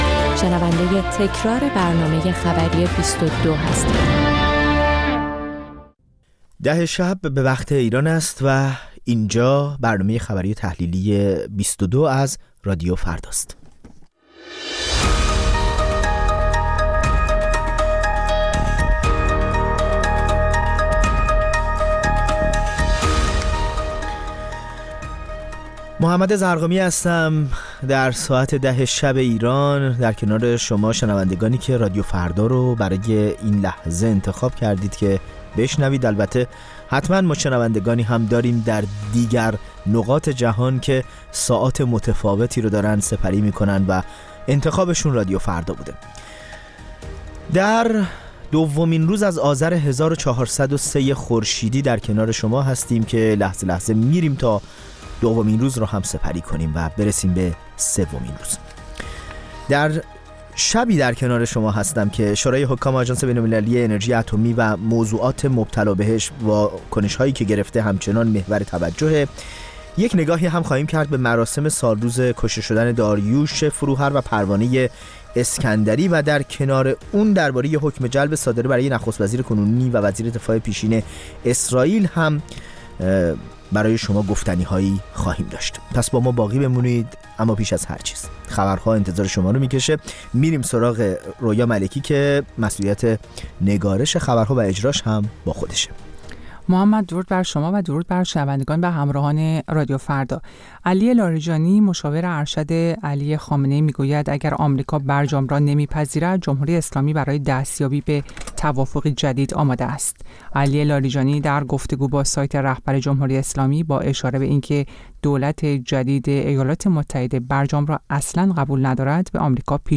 بازپخش برنامه خبری ۲۲